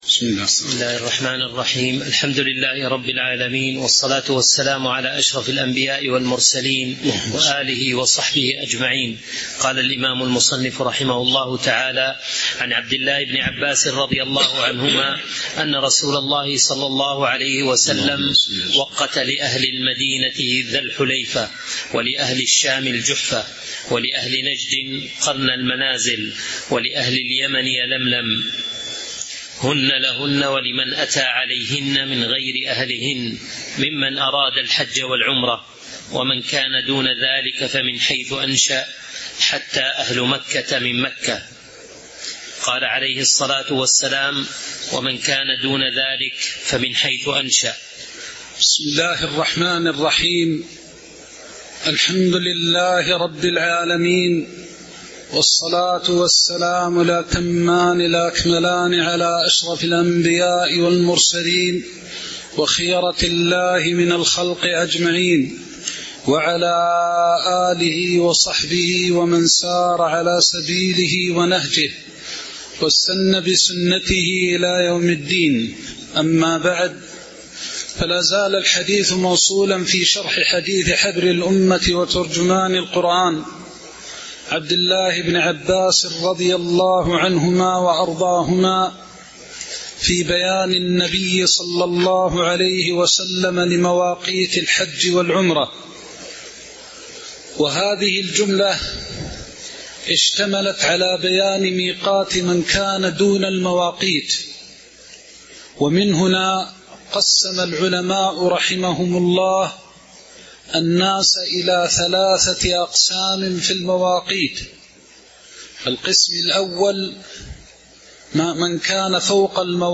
تاريخ النشر ٢٤ رجب ١٤٤٦ هـ المكان: المسجد النبوي الشيخ